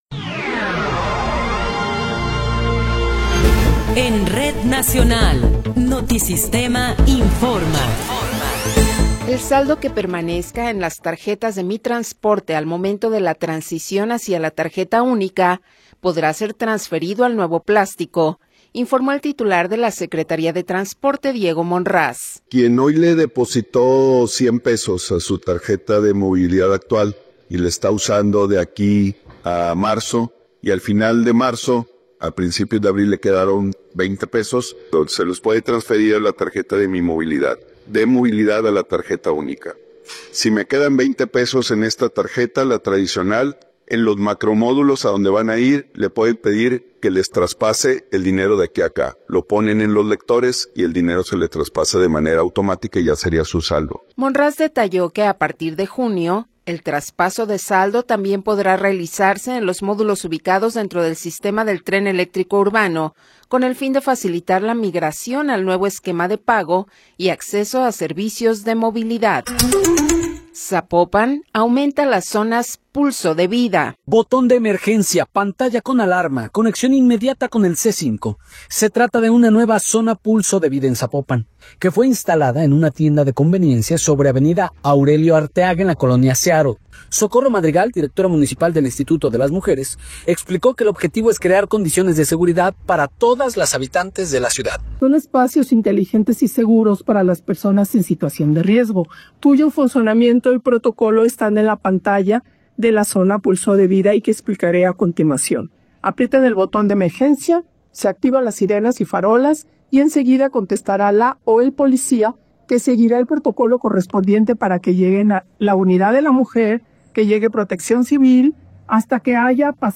Noticiero 15 hrs. – 12 de Enero de 2026
Resumen informativo Notisistema, la mejor y más completa información cada hora en la hora.